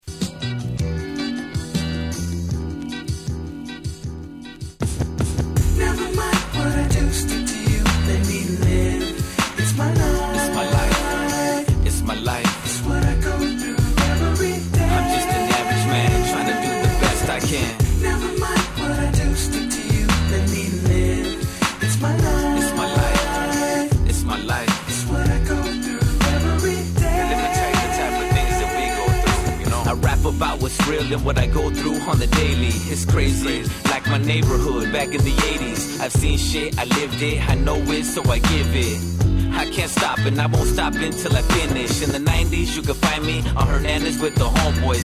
04' Nice チカーノRap !!